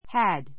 had 中 A1 弱形 həd ハ ド 強形 hǽd ハ ド 動詞 have の過去形・過去分詞 助動詞 had＋過去分詞 で ⦣ 過去完了 かんりょう .